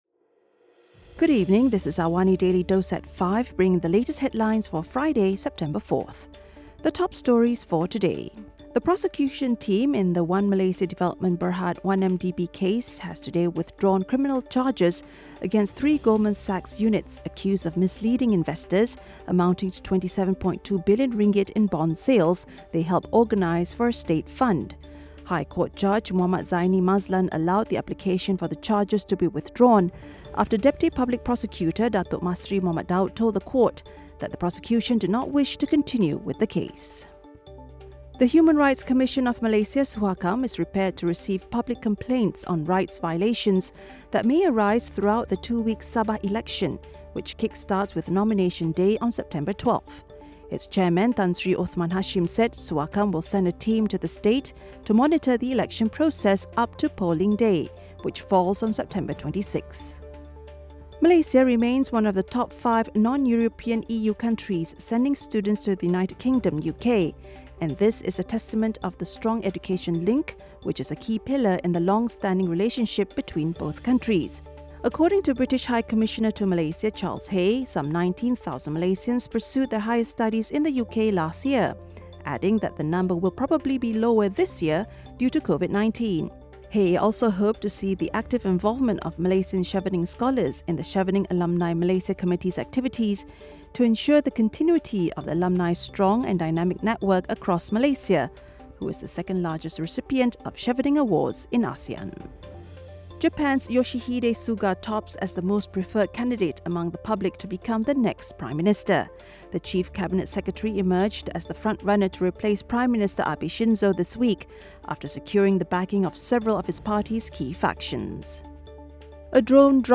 Listen to the top stories of the day, reporting from Astro AWANI newsroom — all in 3 minutes.